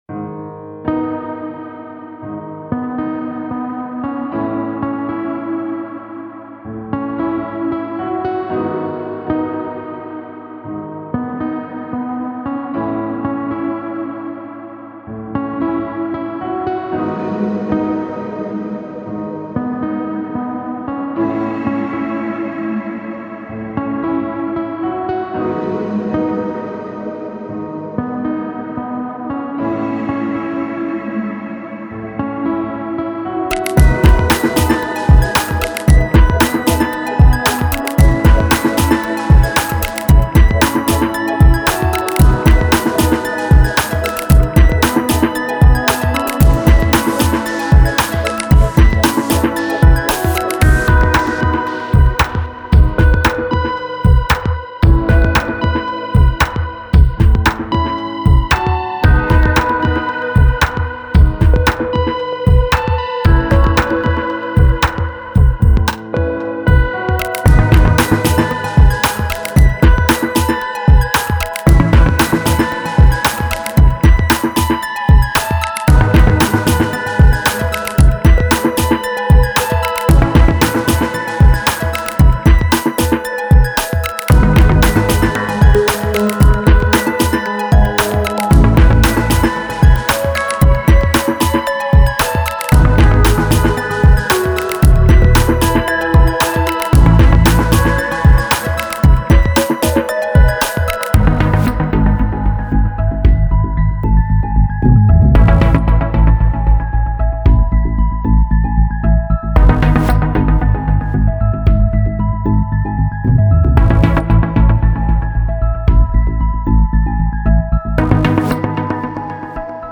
Hip-Hop Instrumentals